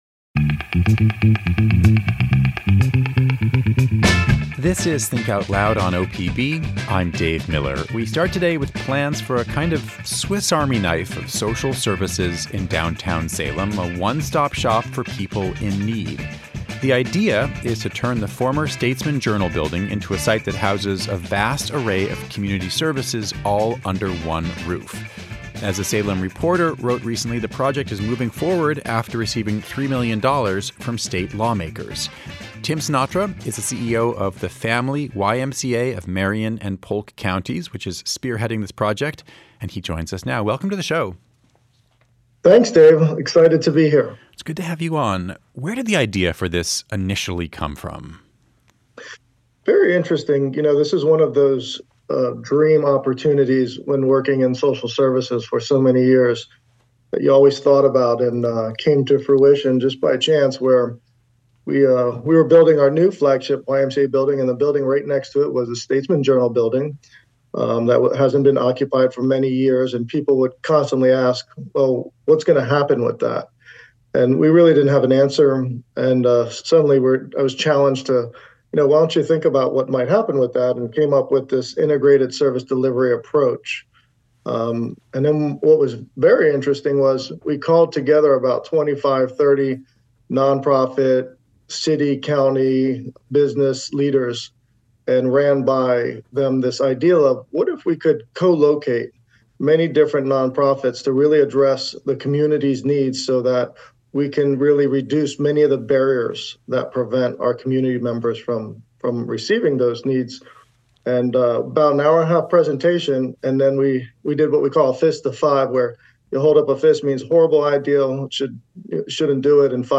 He joins us with more details.